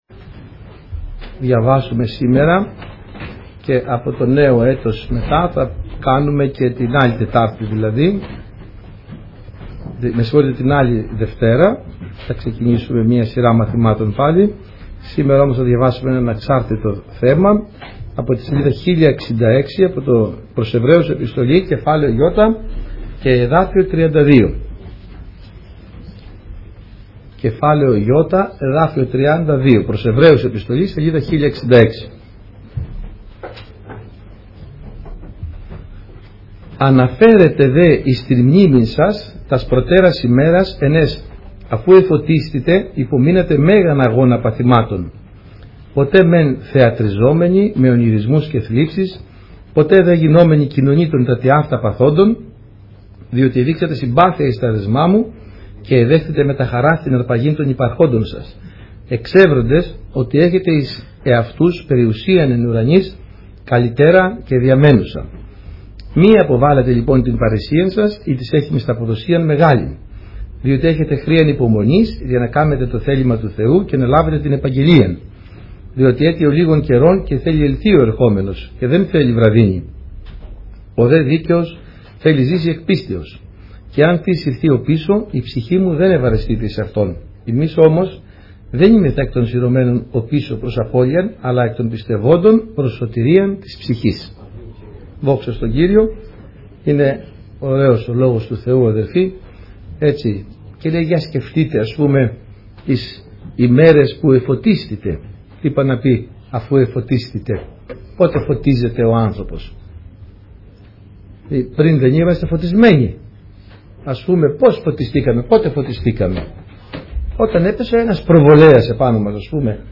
Σειρά: Κηρύγματα